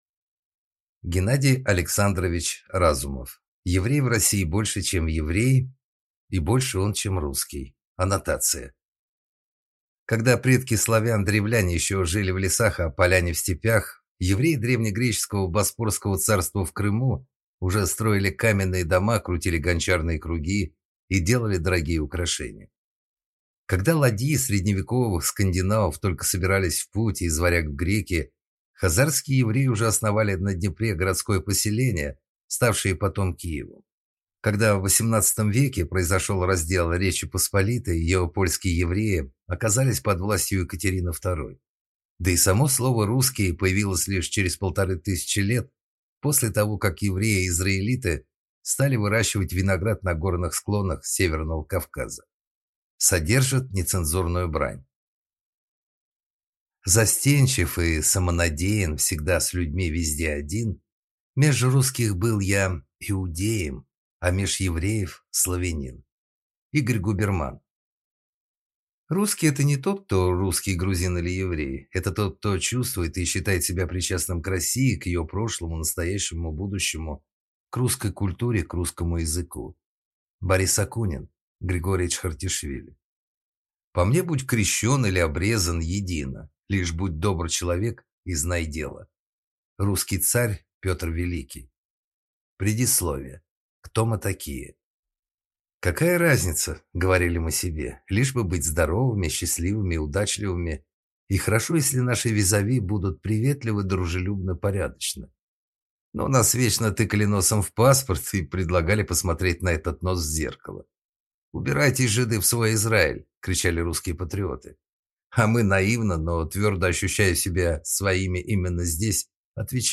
Аудиокнига Еврей в России больше, чем еврей, и больше он, чем русский | Библиотека аудиокниг